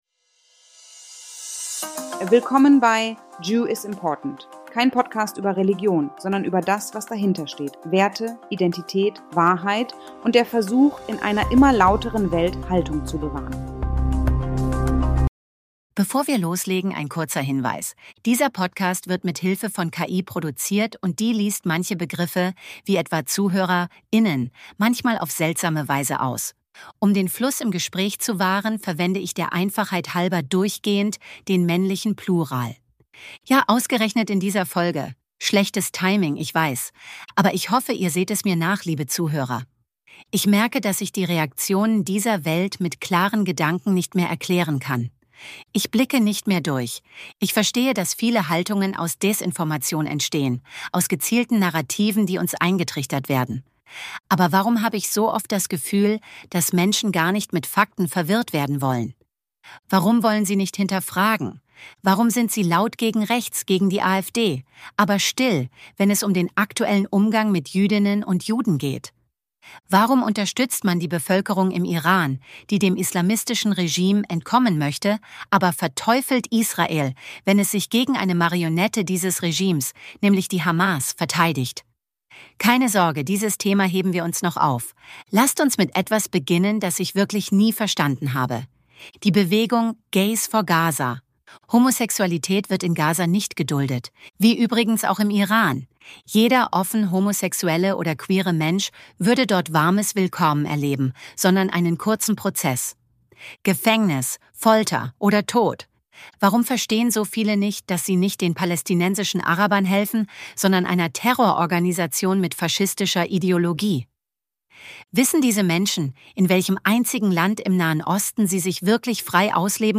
© 2025 AI-generated content.